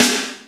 Index of /90_sSampleCDs/Roland L-CDX-01/SNR_Snares 7/SNR_Sn Modules 7
SNR ROCKE0ER.wav